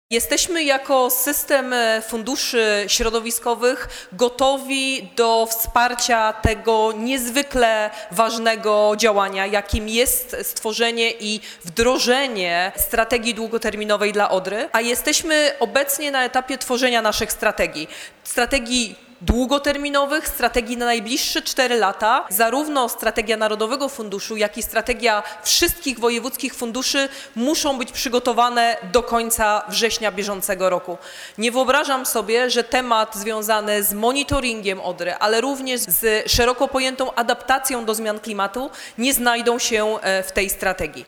W Dolnośląskim Urzędzie Wojewódzkim odbyła się we wtorek debata Ministerstwa Klimatu i Środowiska z przedstawicielami samorządów oraz podmiotami publicznymi nt. współpracy w zakresie zapobiegania zanieczyszczeniom w rzece Odrze.